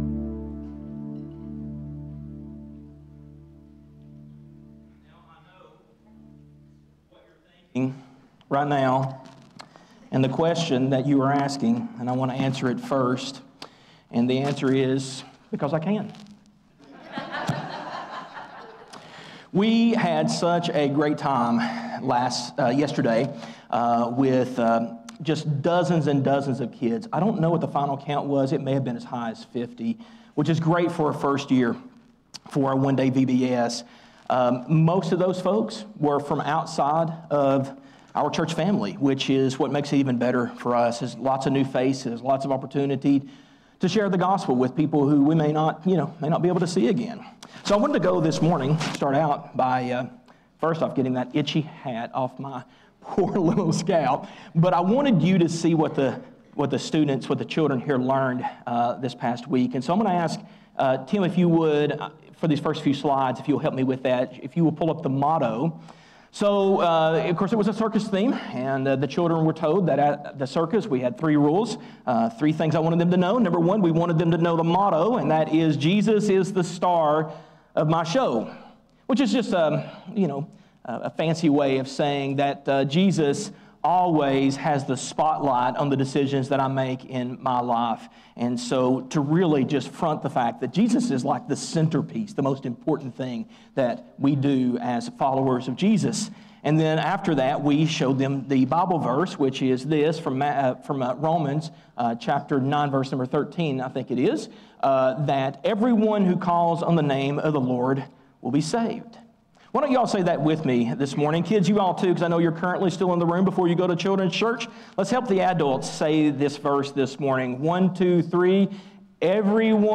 A message from the series "Jesus: The Final Word."